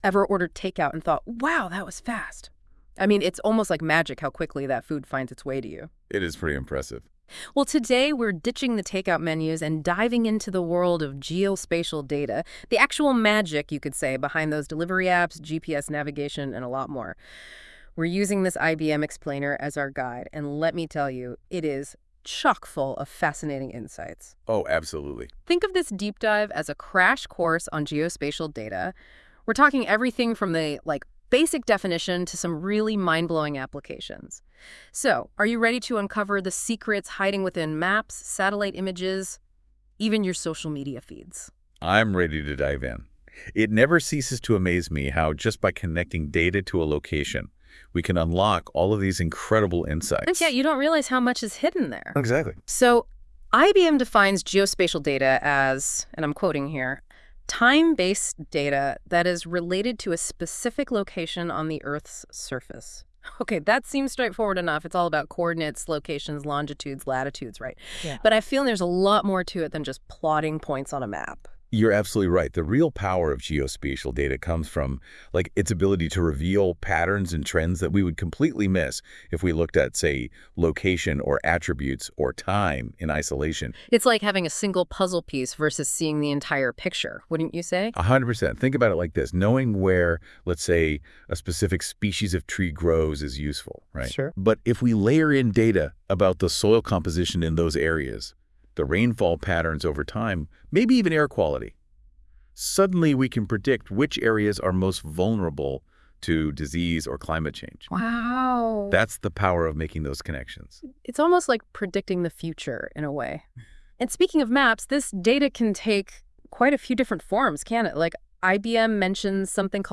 Generated using Google’s NotebookLM based on Geospatial data: the really big picture